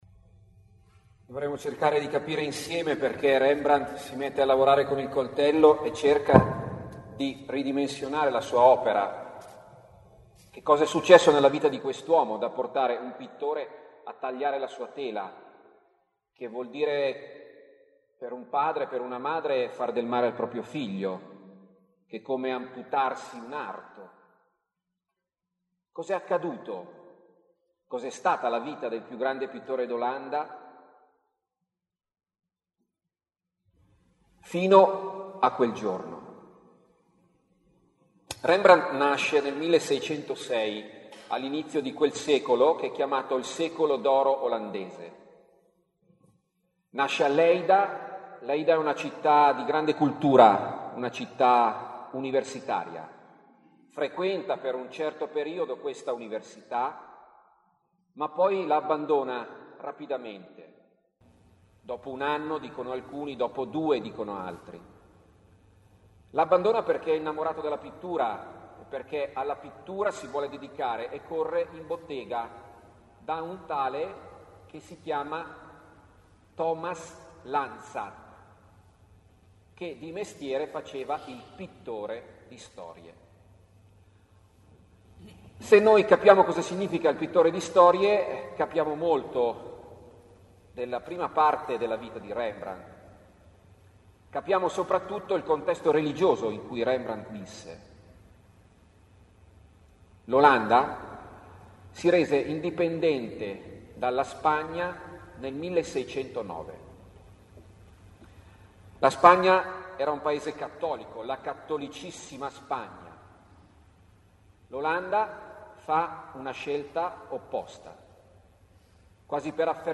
Preghiera quaresimale attraverso l’arte in Santuario: Rembrandt: Un abbraccio benedicente.